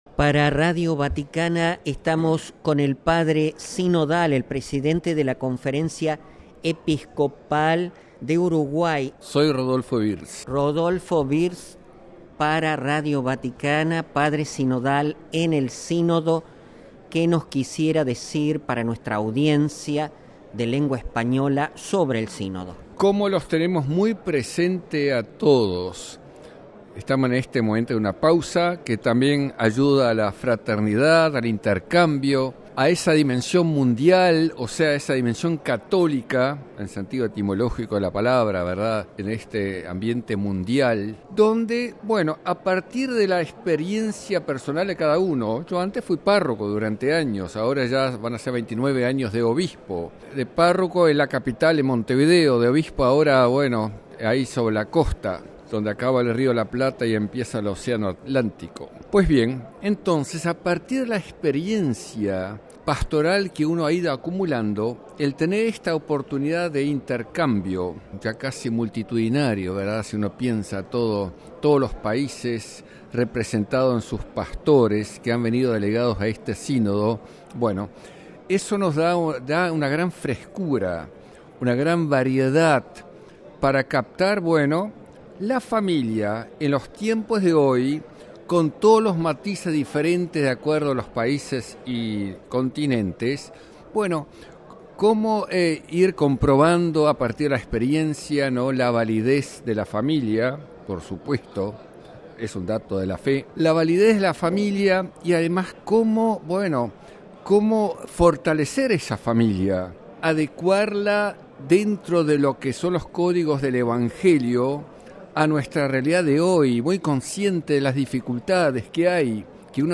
“Todos los días debemos esforzarnos en el diálogo con la familia”, Mons. Wirz, obispo de Uruguay